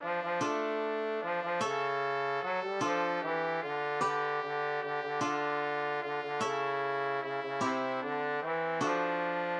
} myMusic = { << \chords { \germanChords \set chordChanges=##t \set Staff.midiInstrument="acoustic guitar (nylon)" s4 | d2.:m | a2.
| d2 } \relative c' { \time 3/4 \partial 4 \tempo 4=150 \key d \minor \set Staff.midiInstrument="trumpet" d4 | d2 d4 | a'2 a4 | a4 g4 f4 | g2 g4 | f2 f4 | e2 e4 | e4 (d4 )c4 | d2 \fine } \addlyrics { \set stanza = "I. " Als Min- ne- sän- ger ward er be- kannt all hier, all dort im gan- __ zen Land. } \relative c { \key d \minor \clef bass \set Staff.midiInstrument="trombone" f8 f8 | a2 f8 f8 | cis2 fis8 g8 | f4 e4 d4 | d4 d4 d8 d8 | d2 d8 d8 | cis2 cis8 cis8 | c4( d4) e4 | f2 \fine } >> } \score { \myMusic \layout { } } \markup { \fill-line { \hspace #1 \column { \line { \bold { II.